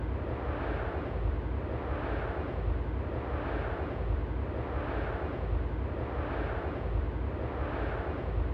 Propagation effects in the synthesis of wind turbine aerodynamic noise | Acta Acustica
Test cases C: xR = 500 m, medium turbulence and grass ground in summer.